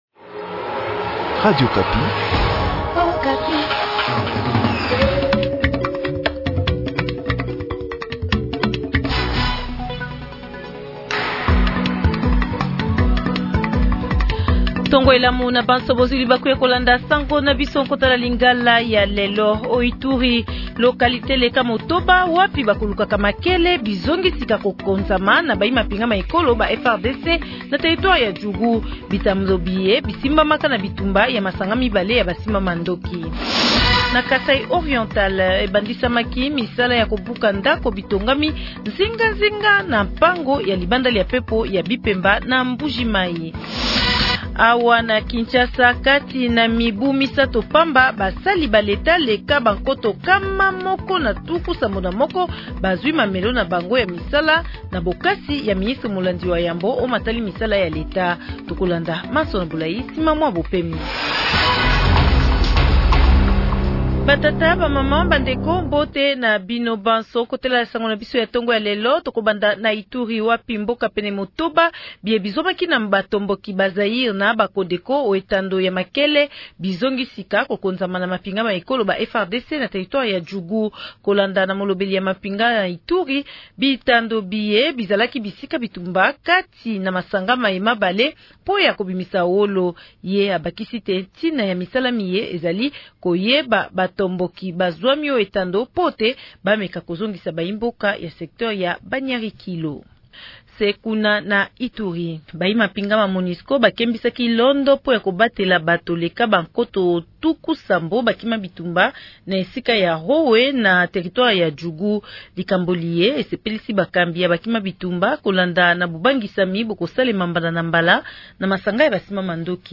Journal Lingala Matin